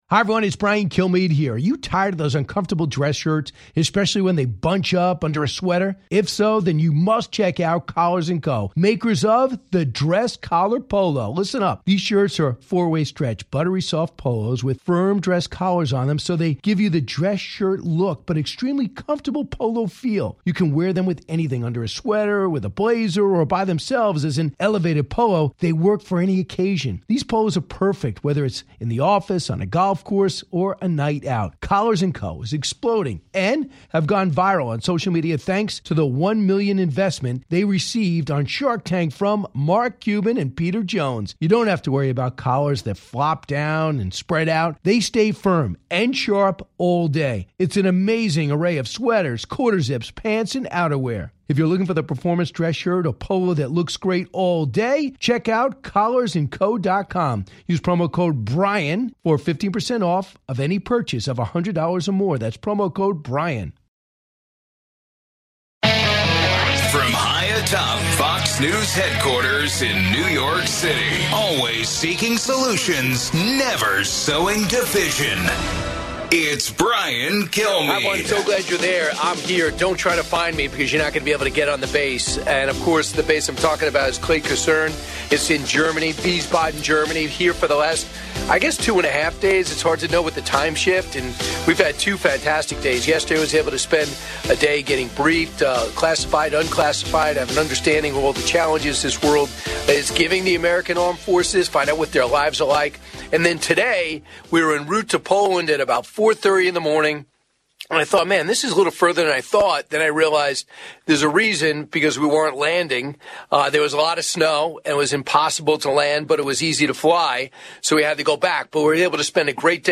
DAY 2 LIVE From Wiesbaden U.S. Army Airfield in Germany